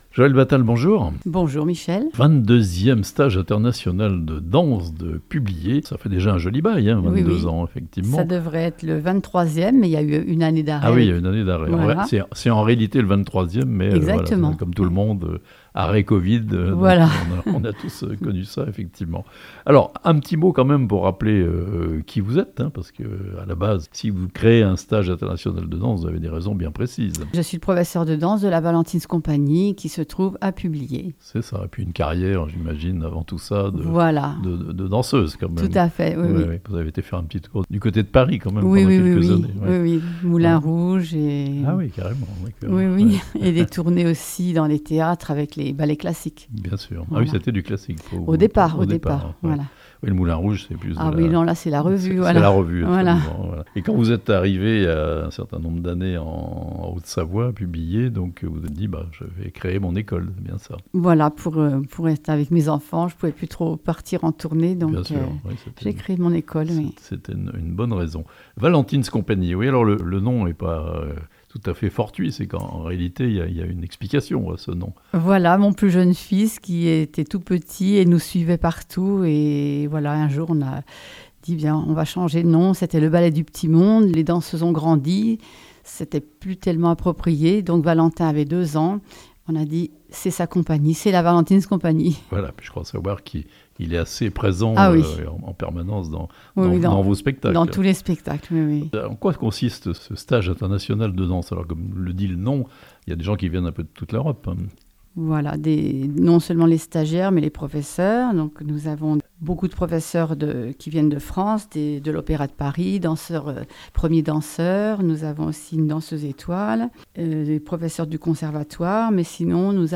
A Publier, un stage international de danse et de théâtre du 13 au 18 août (interview)